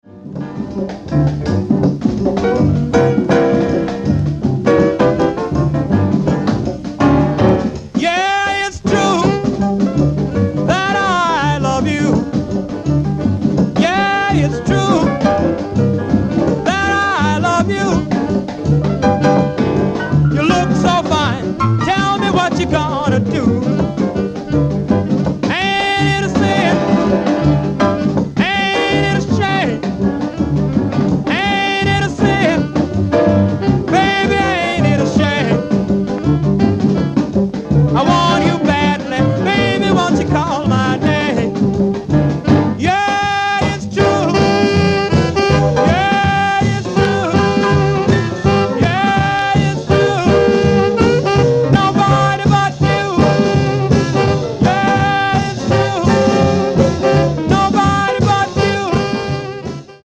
swinging R&B